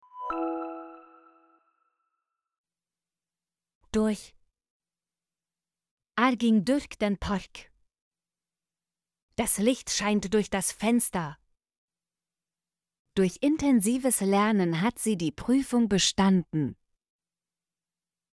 durch - Example Sentences & Pronunciation, German Frequency List